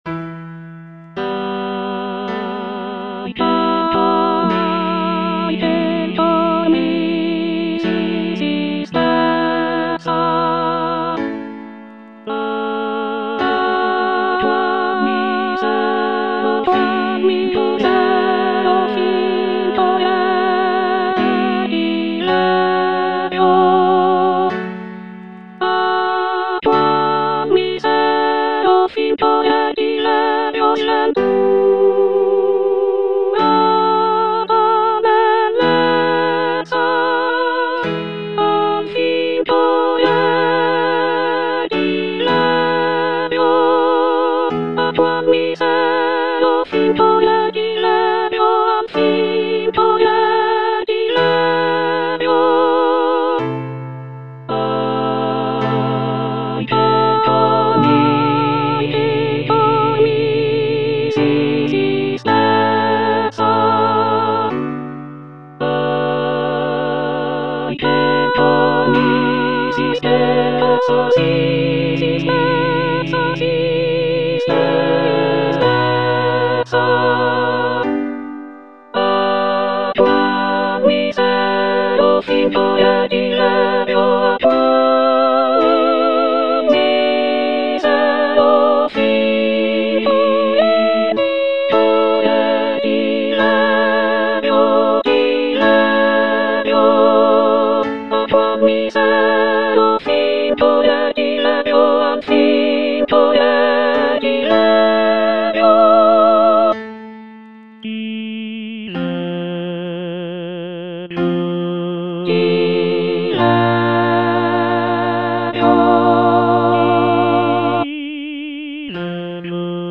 C. MONTEVERDI - LAMENTO D'ARIANNA (VERSION 2) Coro II: Ahi! che'l cor mi si spezza (soprano I) (Emphasised voice and other voices) Ads stop: auto-stop